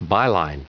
Prononciation du mot byline en anglais (fichier audio)
Prononciation du mot : byline